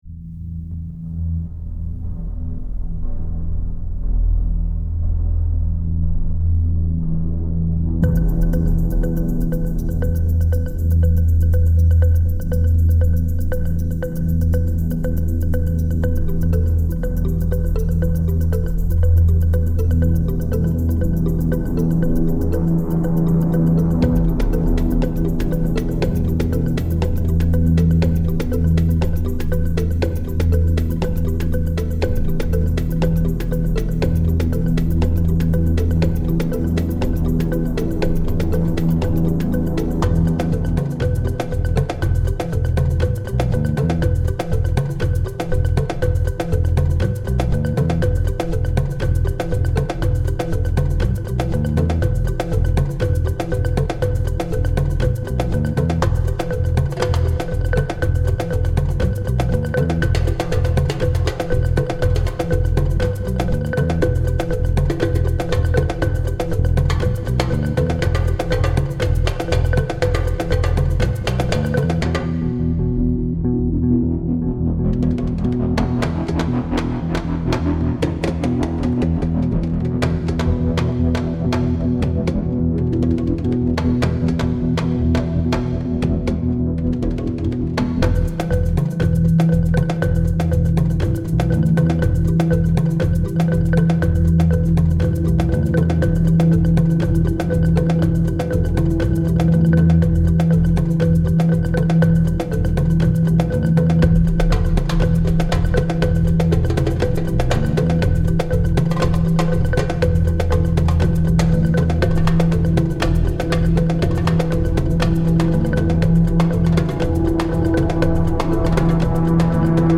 Darker, deeper